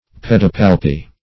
Pedipalpi \Ped`i*pal"pi\, n. pl. [NL. See Pedipalpus.] (Zool.)